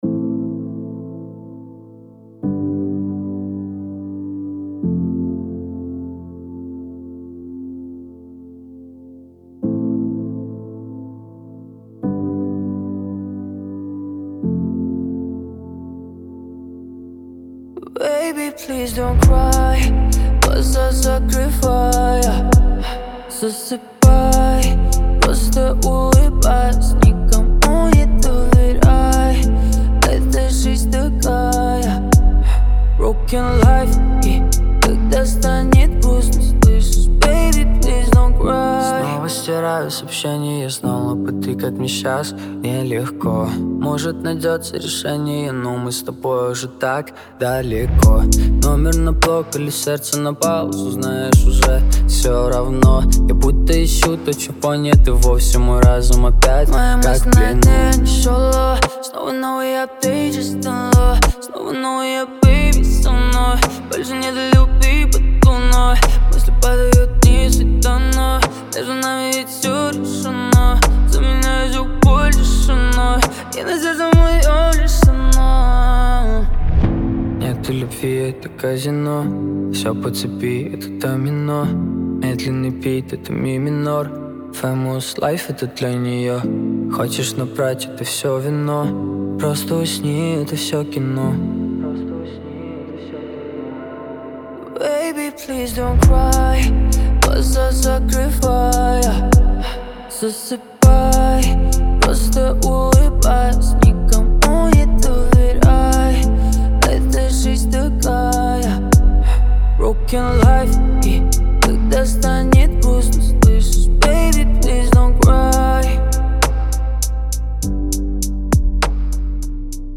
это мощная рок-баллада